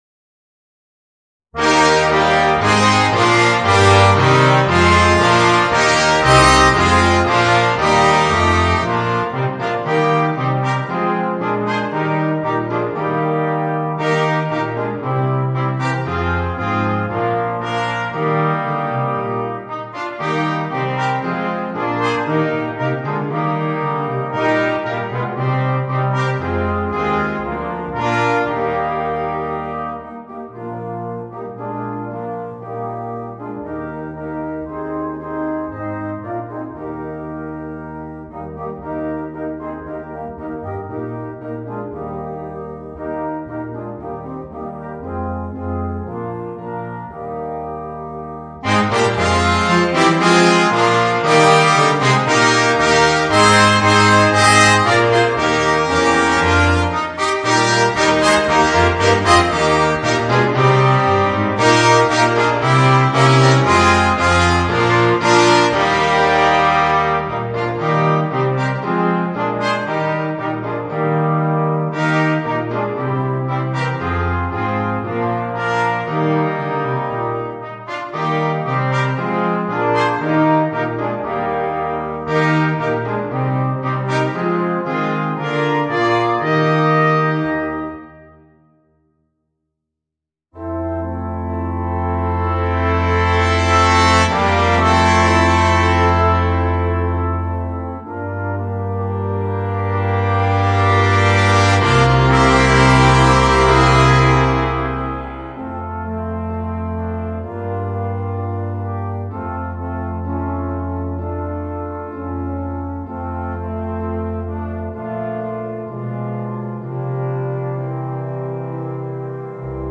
für Blechbläserquintett
Ensemblemusik für 5 Blechbläser
C oder Es Klavier, Orgel und Percussion optional Artikel-Nr.